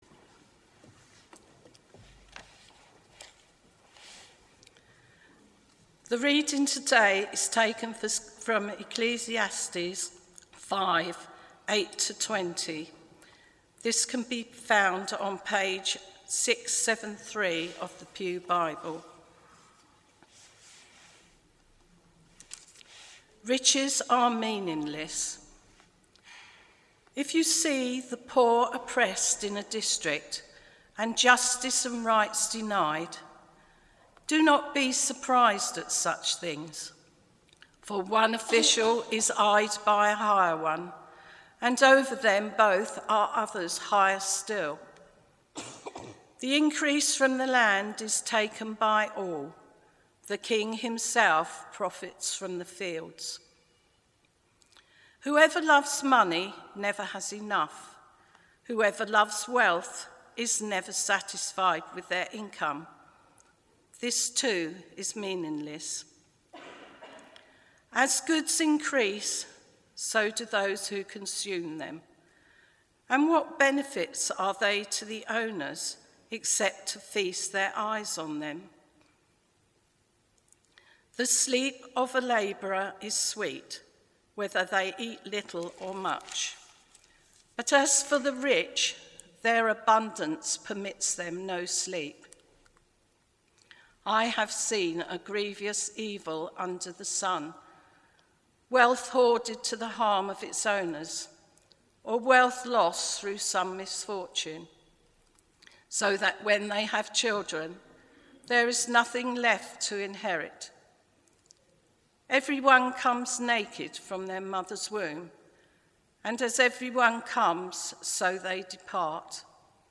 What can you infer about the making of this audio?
Passage: Ecclesiastes 5:8-20 Service Type: Morning Worship « Death